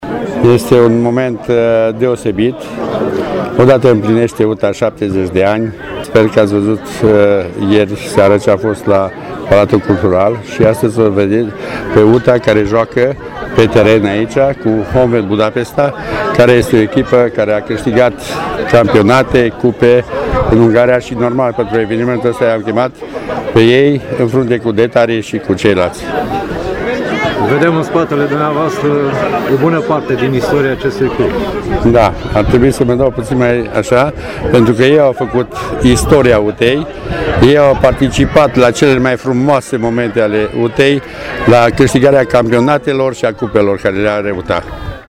Printre invitați s-a numărat și fostul mare fotbalist al UTA-ei, Gheorghe Vaczi: